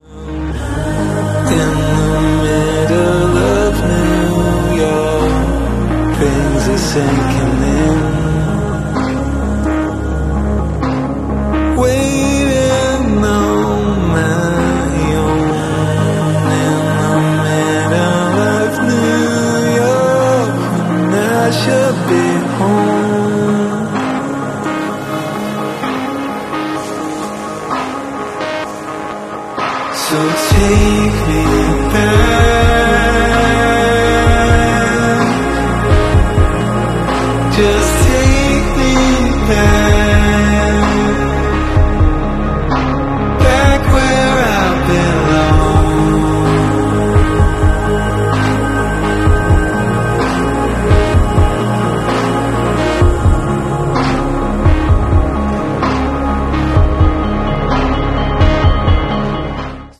Full New York City Cinematic Sound Effects Free Download